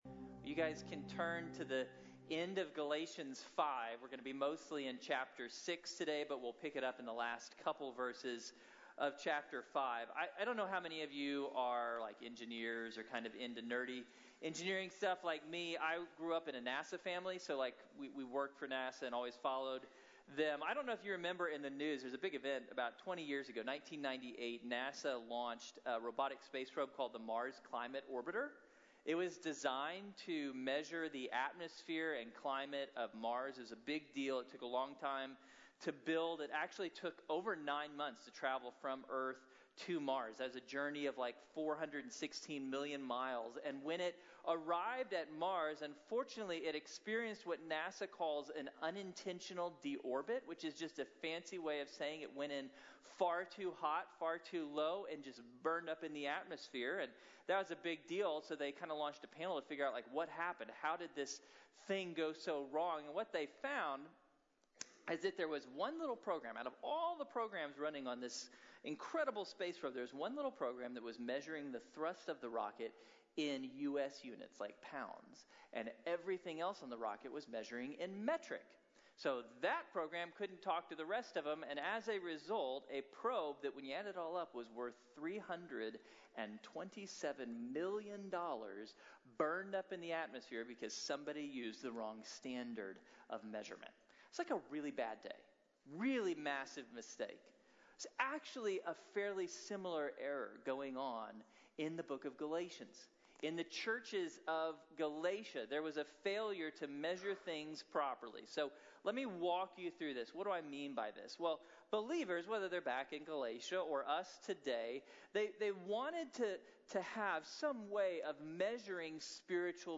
Medida de la Madurez | Sermón | Iglesia Bíblica de la Gracia